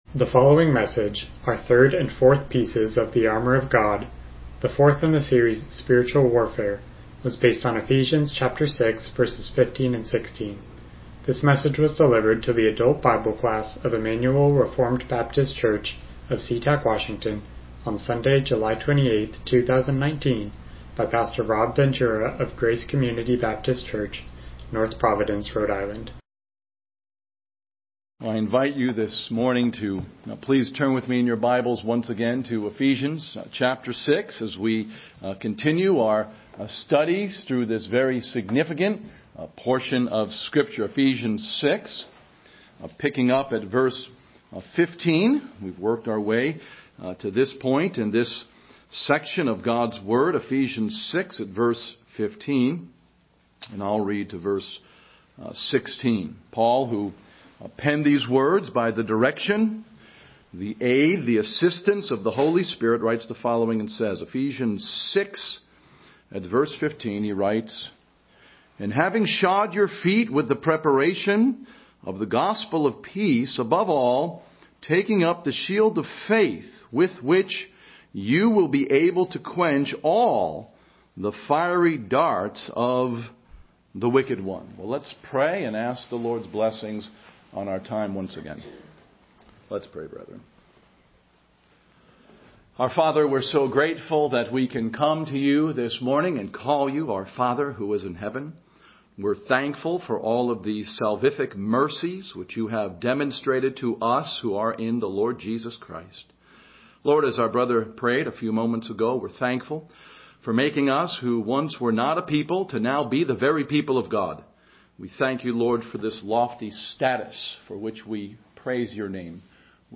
Passage: Ephesians 6:15-16 Service Type: Sunday School